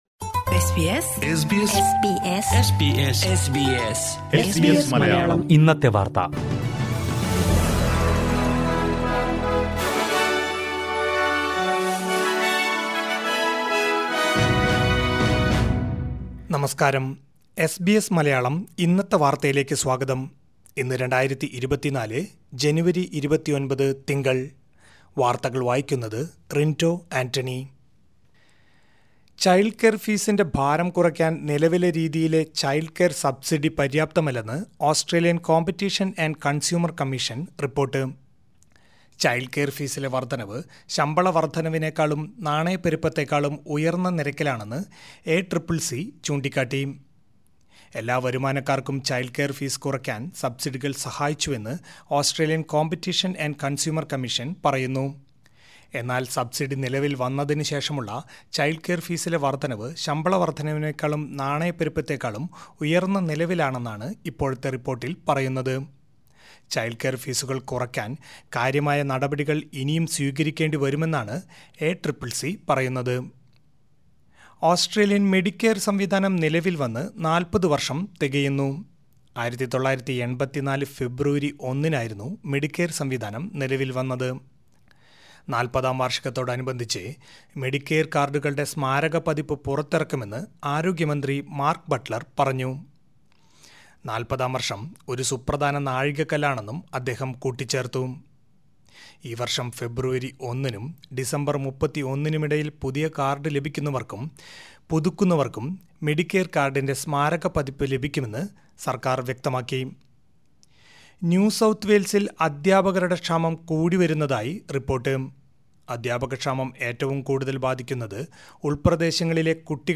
2024 ജനുവരി 29ലെ ഓസ്‌ട്രേലിയയിലെ ഏറ്റവും പ്രധാനപ്പെട്ട വാര്‍ത്തകള്‍ കേള്‍ക്കാം.